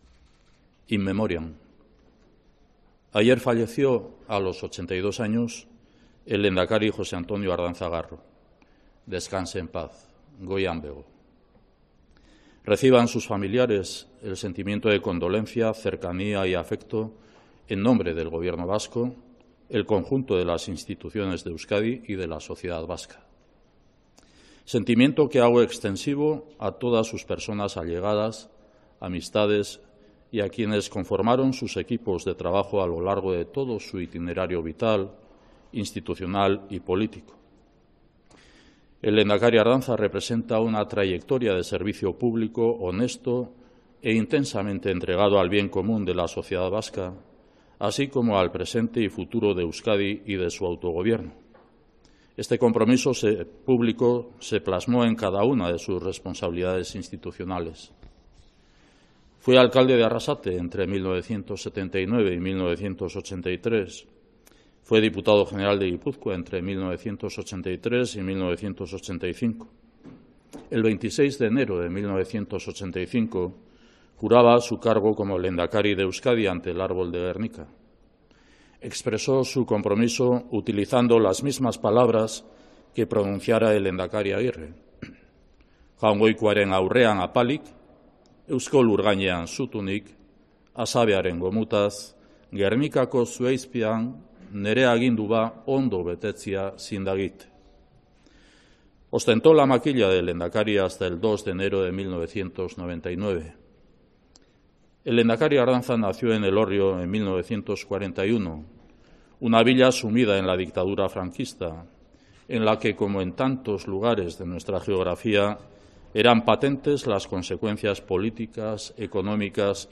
Declaración del lehendakari Urkullu ante el fallecimiento del lehendakari Ardanza
El lehendakari Íñigo Urkullu ha comparecido este martes en la sede de la presidencia vasca en Vitoria para leer una declaración en nombre del Ejecutivo autonómico ante el fallecimiento este lunes a los 82 años de edad del lehendakari Jose Antonio Ardanza, que en años de plomo etarra y de crisis económica tejió acuerdos entre diferentes y auspició el Pacto de Ajuria Enea para arrinconar al terrorismo y a quienes lo apoyaban.